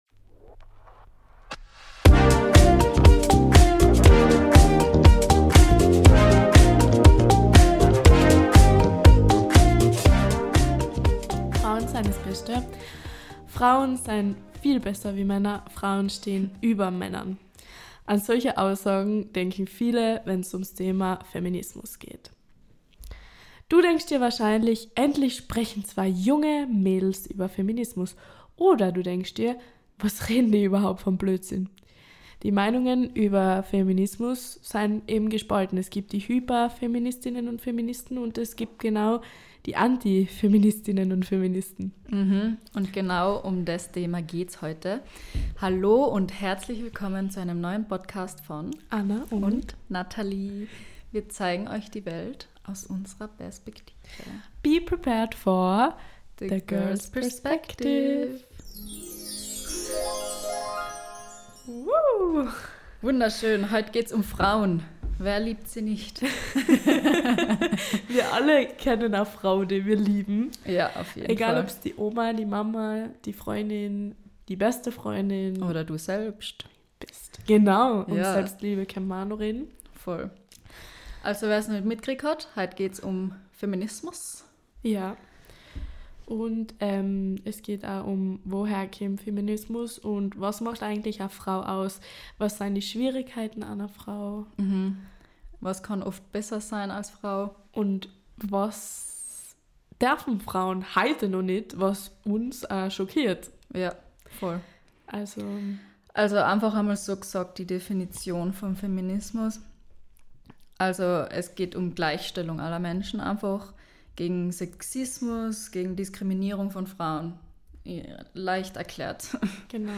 lockeren Plauder-Podcast